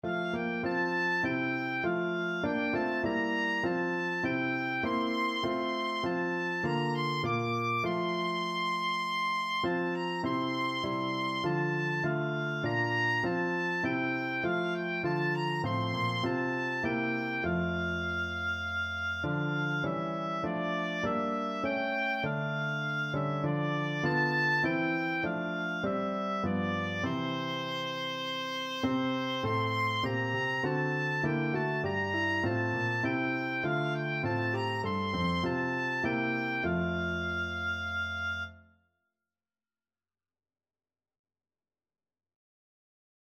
Christmas Christmas Oboe Sheet Music It Came Upon the Midnight Clear
Oboe
4/4 (View more 4/4 Music)
F major (Sounding Pitch) (View more F major Music for Oboe )
Classical (View more Classical Oboe Music)